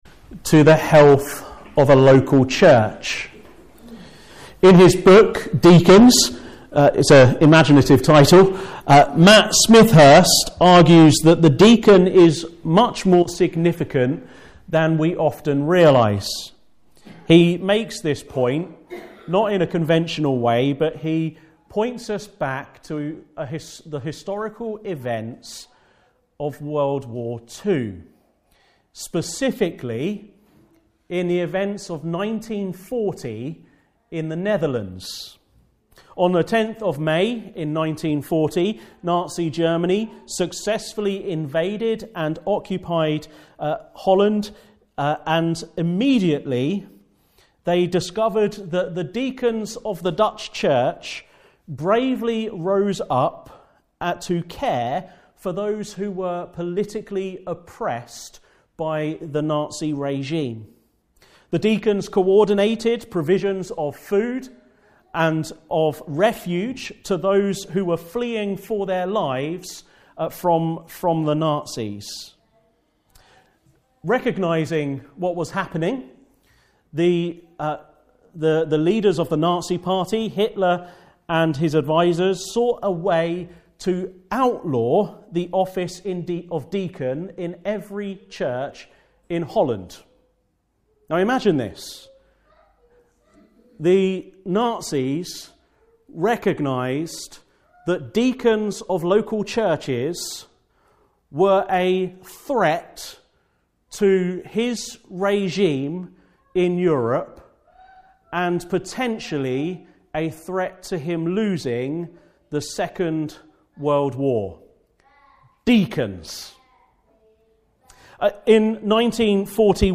Service Type: Afternoon Service